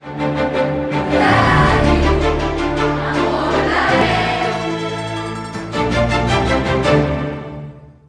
Indicatiu cantat de la ràdio